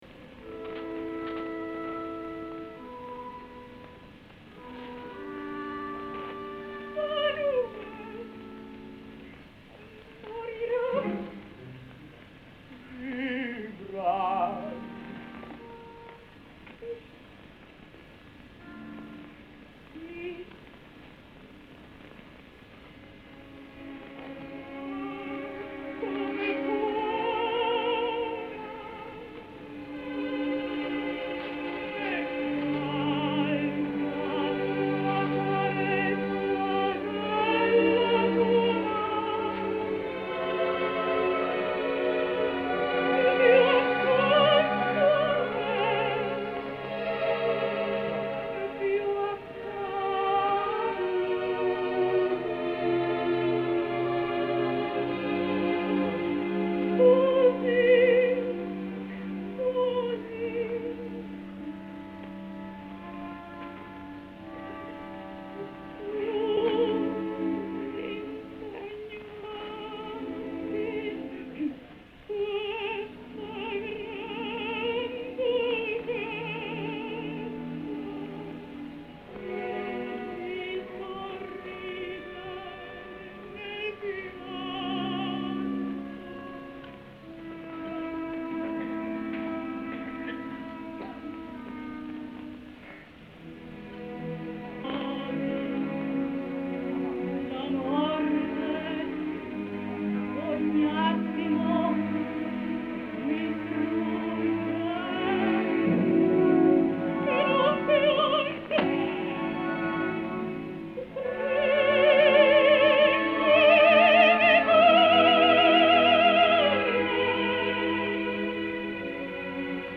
A la Scala va seguir, ara sí, amb els primers rols que li varen denegar durant tants anys, amb una Rosina de Il Barbiere i amb la Rubria del Nerone de Boito sota la direcció del mític i temut Toscanini que des de els inicials assajos va quedar corprès pel cant de la mezzosoprano. Escoltem de l’òpera Nerone d’Arrigo Boito, a la Rubria de Giulietta Simionato en el fragment del  Act IV  Fanuel … moriro’, escoltarem a Frank Guarrera com a Fanuel, dirigits per Arturo Toscanini, el dia 10 de juny de 1948 a la Scala de Milà.